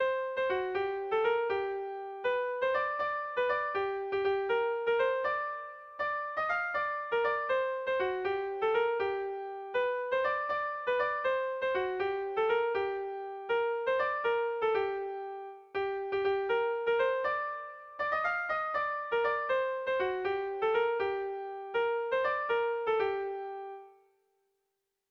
Erromantzea
Hamabiko txikia (hg) / Sei puntuko txikia (ip)
ABAA2BA2